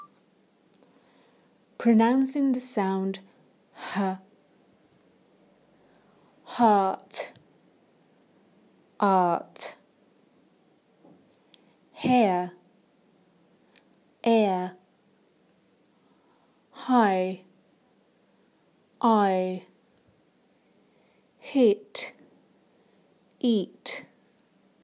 Listen to these pairs of words. In the words on the left the first sound is /h/. In the words of the right the first sound is a vowel, can you hear the difference?